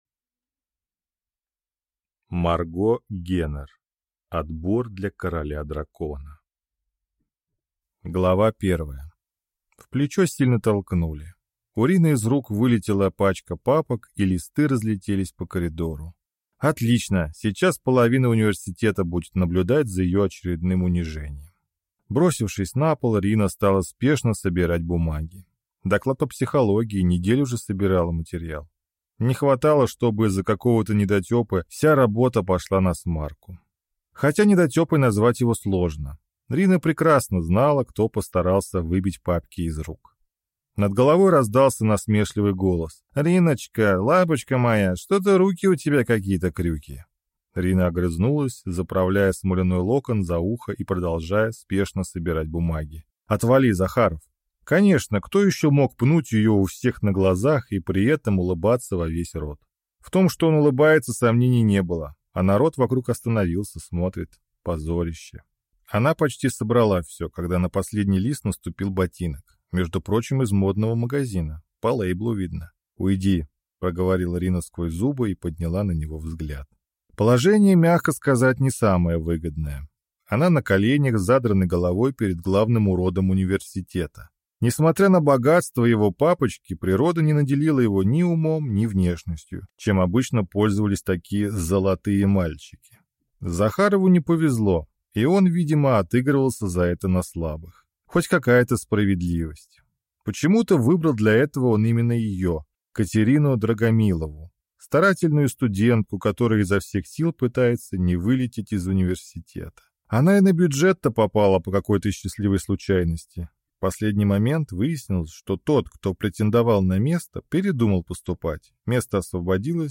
Aудиокнига Отбор для короля-дракона